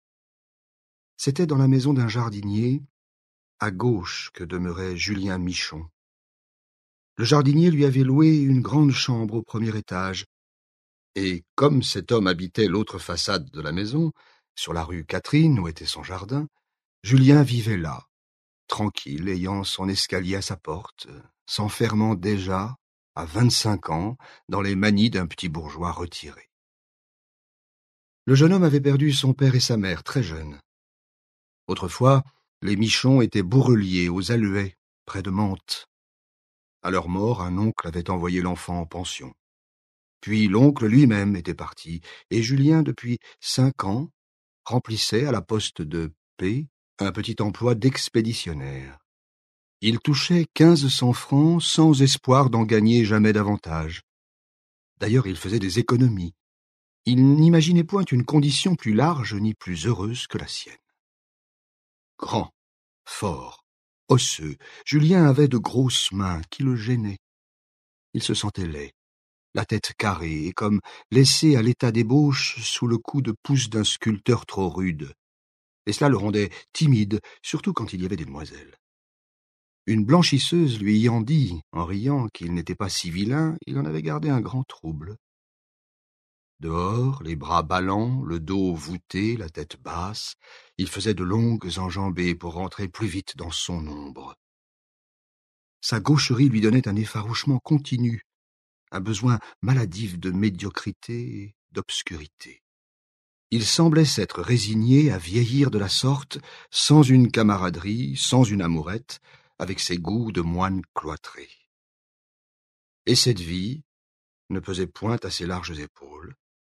Click for an excerpt - Pour une nuit d'amour de Emile Zola